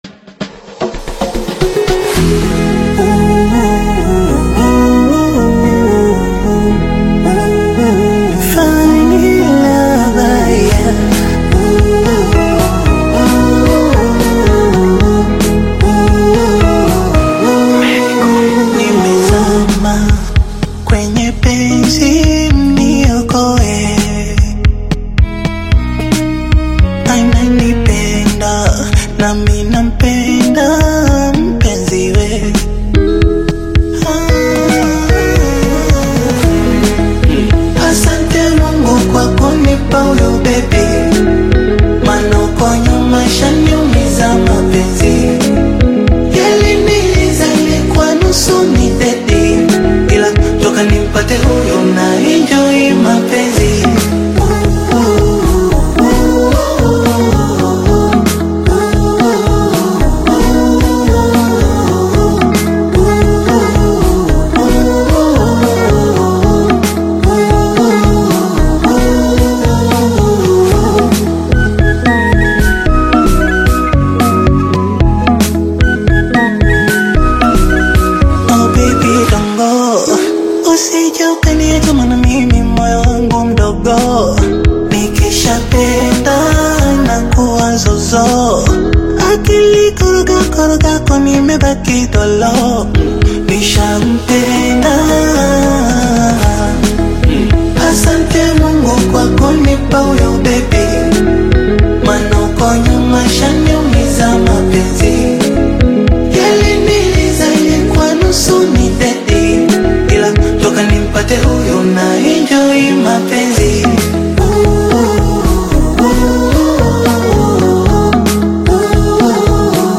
Tanzanian Bongo Flava/Afro-Pop single
blends melodic hooks with modern Afro-inspired rhythms
expressive delivery and heartfelt storytelling